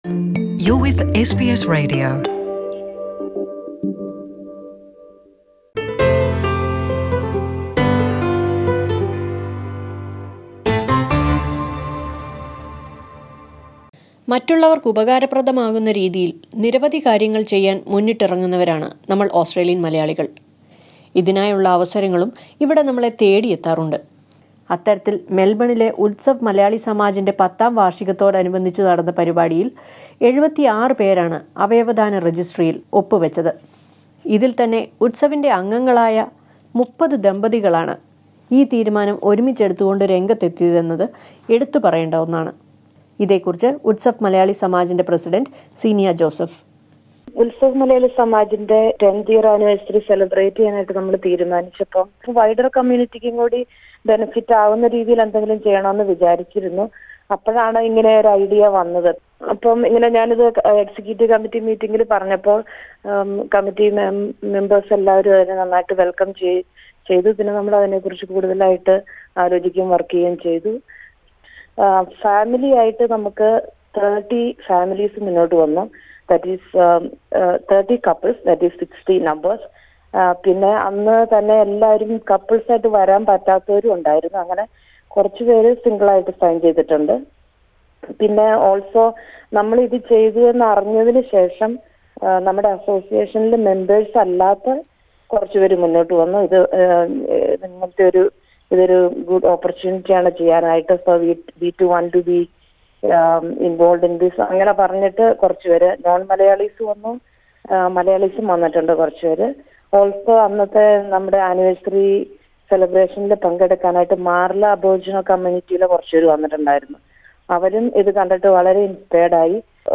മെൽബണിലെ ഉത്സവ് മലയാളി സാമാജിന്റെ പത്താം വാർഷികത്തോടനുബന്ധിച്ച് നടന്ന പരിപാടിയിൽ 30 ജോഡി ദമ്പതികൾ അവയവദാന രജിസ്ട്രിയിൽ ഒപ്പു വയ്ക്കാൻ മുൻപോട്ടു വന്നിരുന്നു. ഇതിൽ ചില ദമ്പതികൾ എസ് ബി എസ് മലയാളത്തോട് സംസാറിച്ചത് കേൾക്കാം മുകളിലെ പ്ലേയറിൽ നിന്ന്...